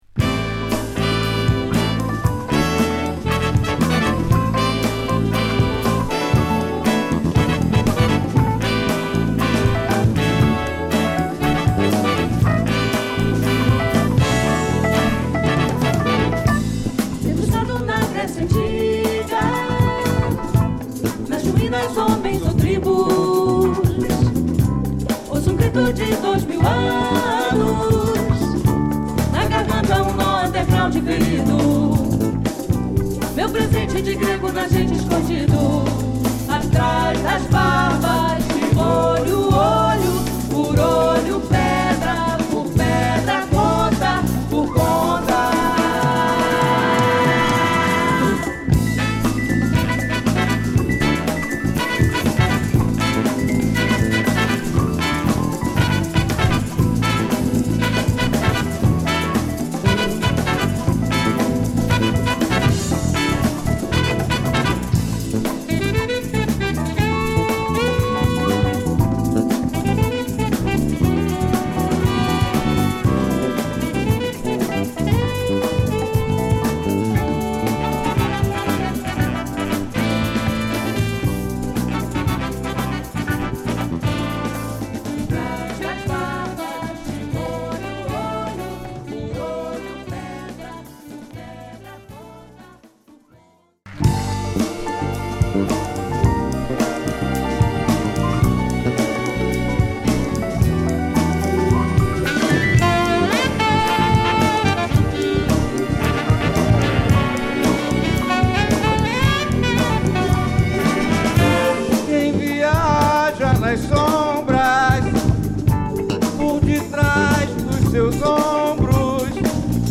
オリジナルのサヴタージ感を損なう事無く壮大なサウンドを披露！